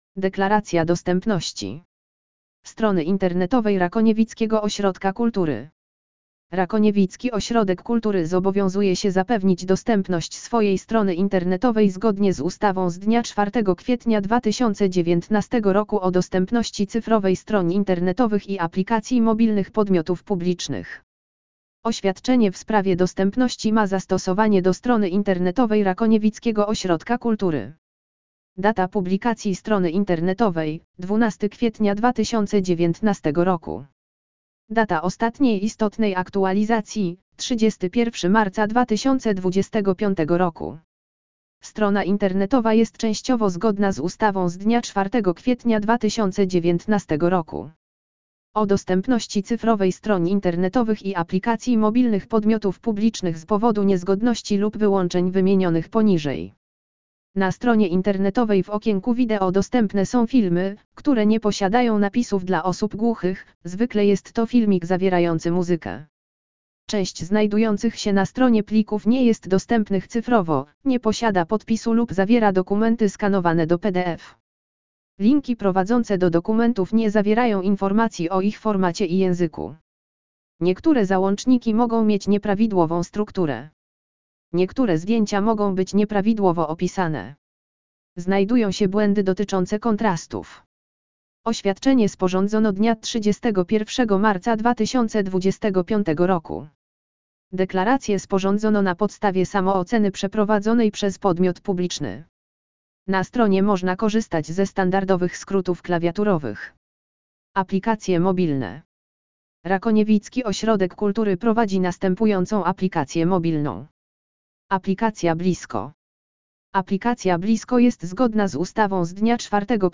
AUDIO LEKTOR DEKLARACJA DOSTĘPNOŚCI Deklaracja dostępności strony internetowej Rakoniewickiego Ośrodka Kultury Rakoniewicki Ośrodek Kultury zobowiązuje się zapewnić dostępność swojej strony internetowej zgodnie z ustawą z dnia 4 kwietnia 2019 r. o dostępności cyfrowej stron internetowych i aplikacji mobilnych podmiotów publicznych.